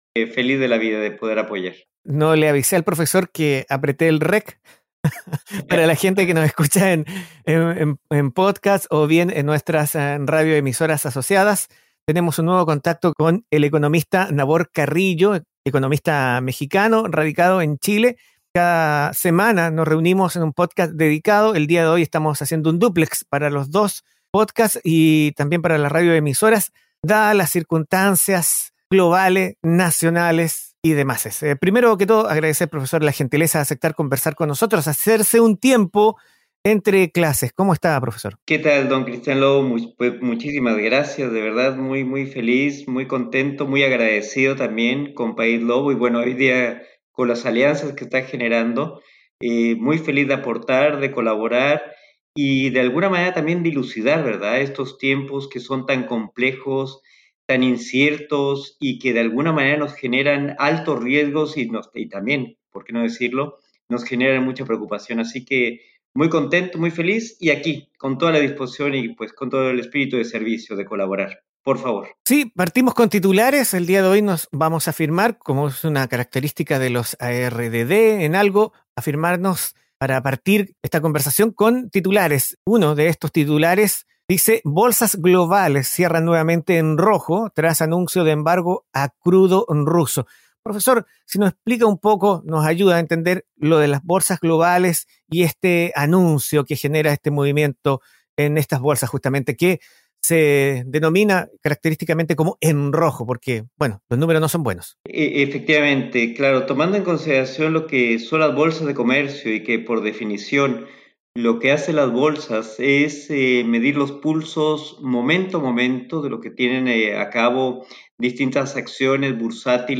En esta conversación revisamos como las bolsas globales cierran nuevamente en rojo tras anuncio de embargo a crudo ruso; el Gobierno anuncia modificación del Mepco; el riesgo de caer en "estanflación" en Chile; y el anunció del Banco Central ruso que limita a 10.000 dólares la retirada de dólares de cuentas.